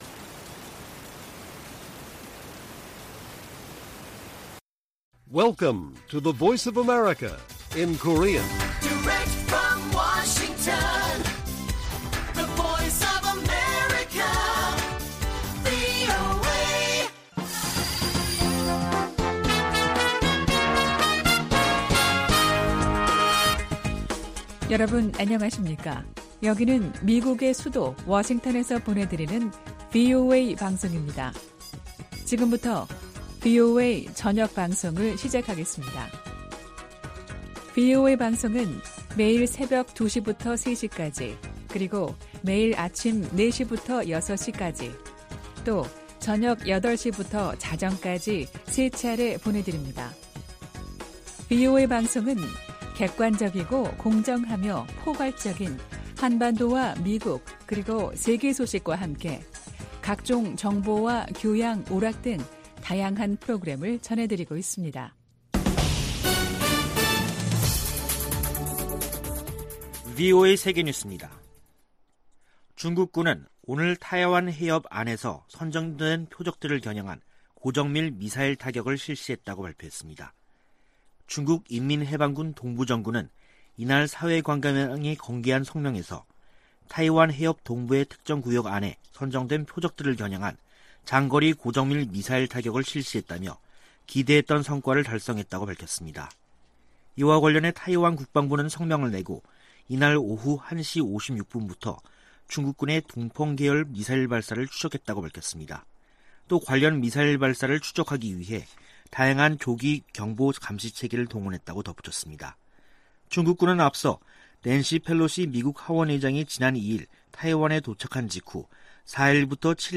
VOA 한국어 간판 뉴스 프로그램 '뉴스 투데이', 2022년 8월 4일 1부 방송입니다. 윤석열 한국 대통령이 낸시 펠로시 미 하원의장의 방한 행보에 관해 미한 대북 억지력의 징표라고 말했습니다. 미국은 한국에 대한 확장억제 공약에 매우 진지하며, 북한이 대화를 거부하고 있지만 비핵화 노력을 계속할 것이라고 국무부가 강조했습니다. 미국과 한국의 합참의장이 화상대화를 갖고 동맹과 군사협력 등에 관해 논의했습니다.